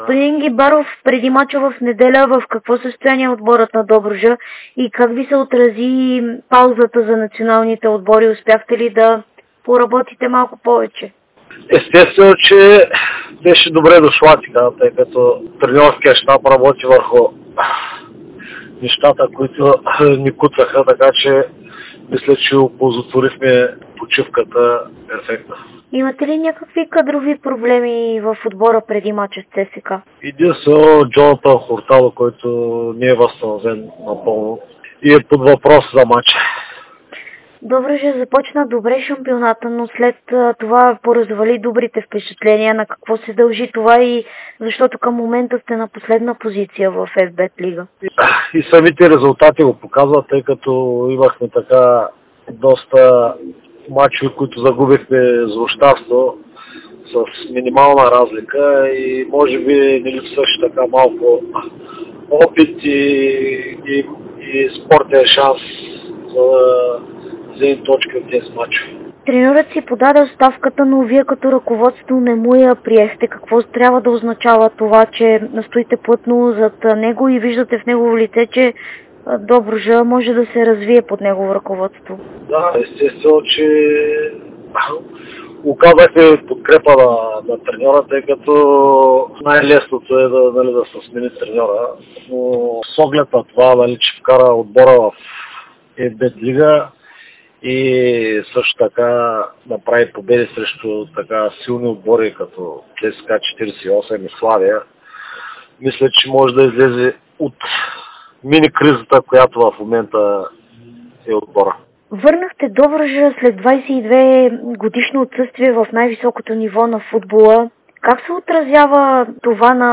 говори ексклузивно пред Дарик радио и Dsport преди домакинството на тима срещу ЦСКА от 12-ия кръг на българското първенство.